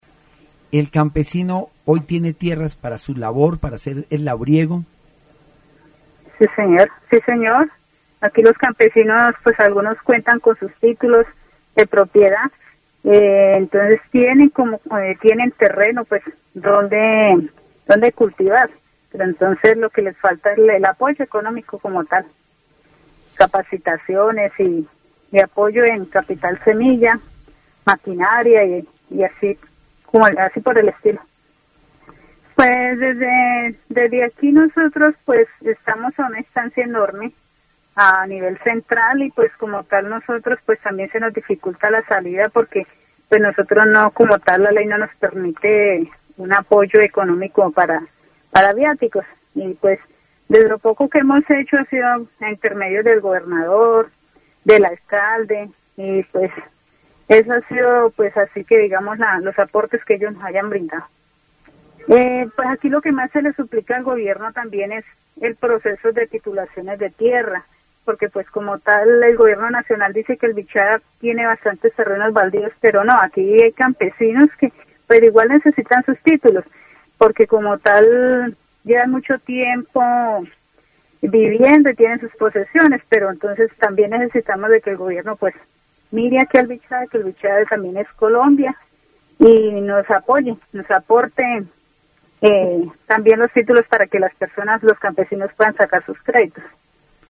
Entrevista sobre las tierras y el apoyo a los campesinos en Vichada, enfocándose en la falta de respaldo económico, la necesidad de capacitación y el proceso de titulación de tierras.